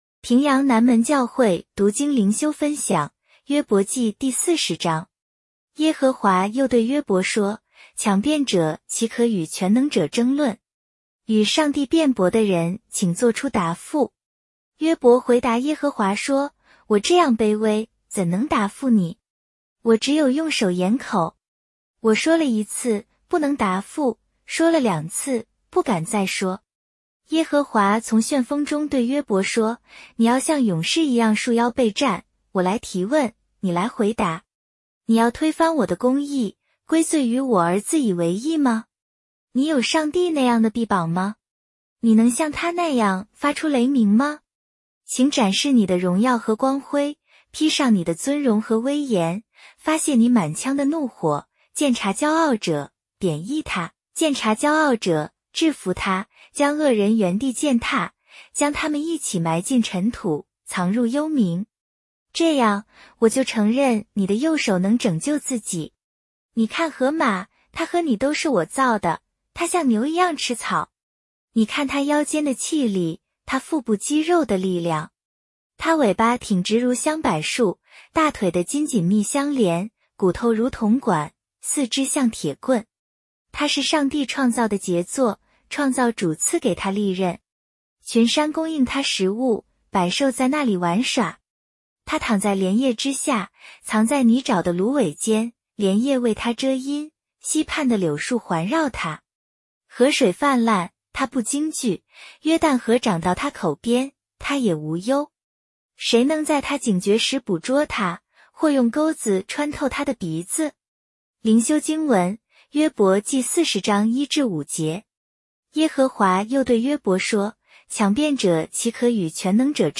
普通话朗读——伯40